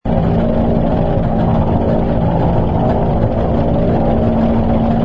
engine_bw_fighter_loop.wav